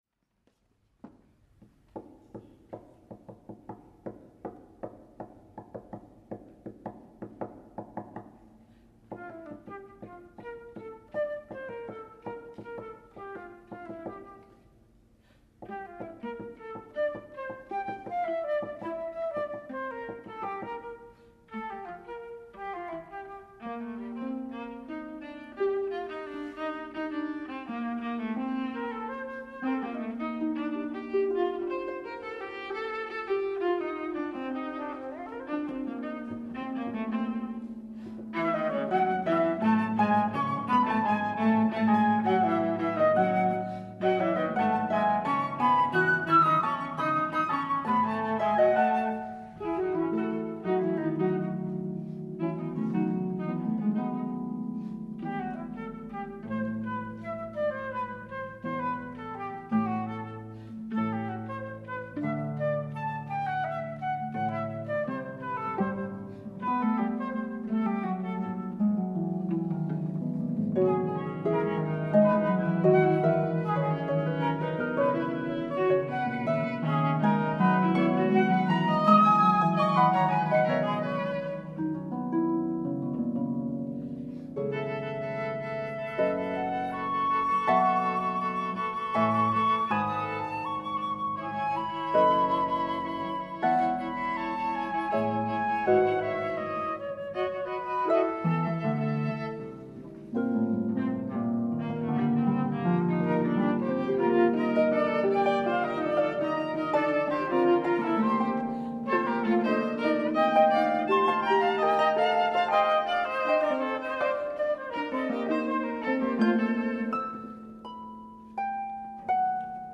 This lively piece
Flute, viola, harp